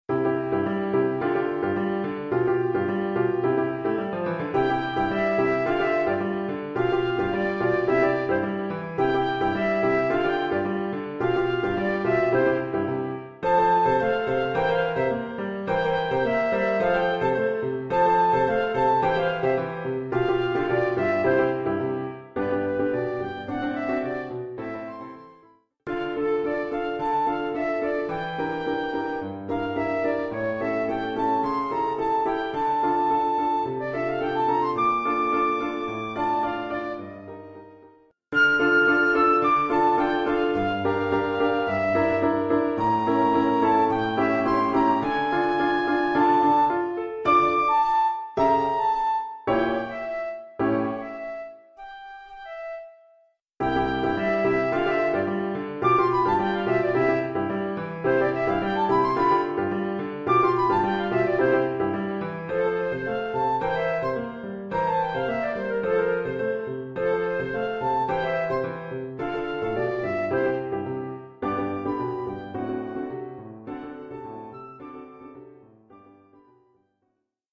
1 titre, ¨flûte et piano : conducteur et partie de flûte ut
Oeuvre pour flûte avec accompagnement de piano.
Niveau : fin du 1er cycle.